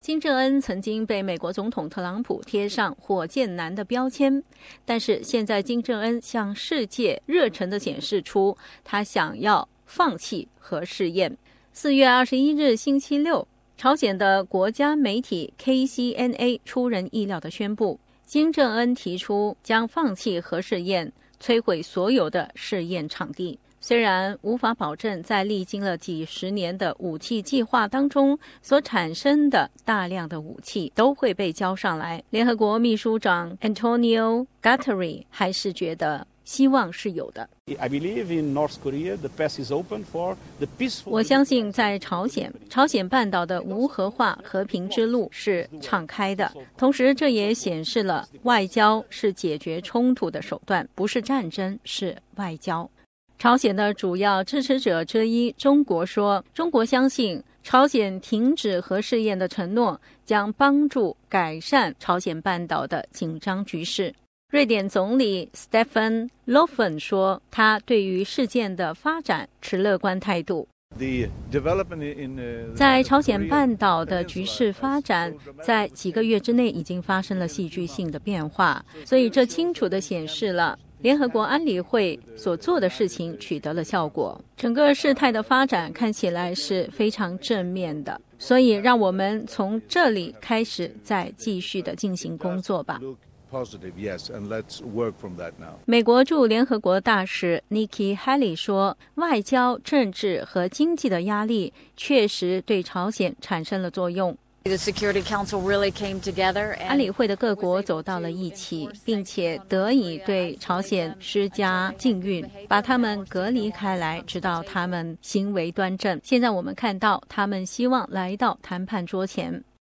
Source: AAP SBS 普通话电台 View Podcast Series Follow and Subscribe Apple Podcasts YouTube Spotify Download (2.22MB) Download the SBS Audio app Available on iOS and Android 朝鲜国家电视台宣布金正恩将放弃其核武器计划，引起国际社会不同的反响。